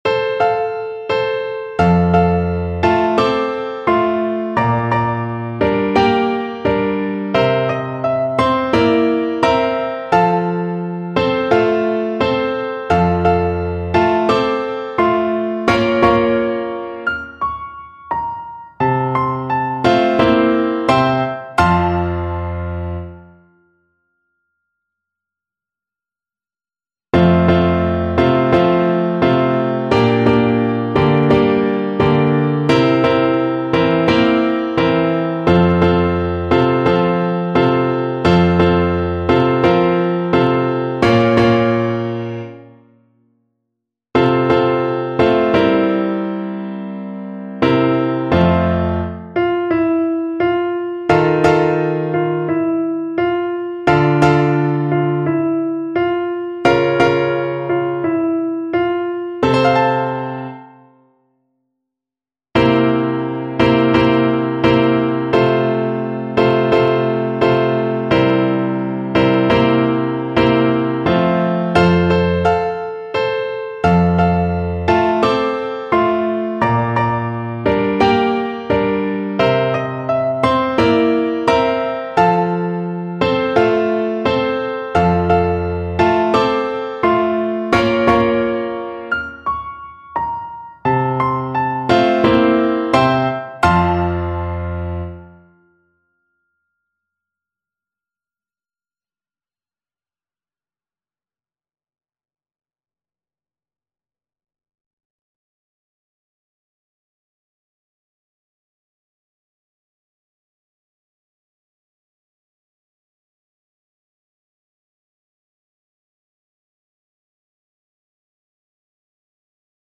Violin version
4/4 (View more 4/4 Music)
Gracioso = 60
Classical (View more Classical Violin Music)